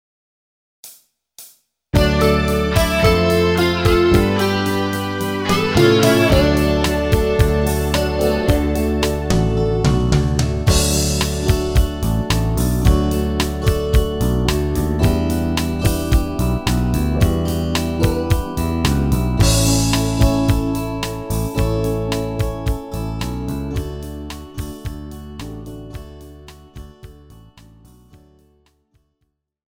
KARAOKE/FORMÁT: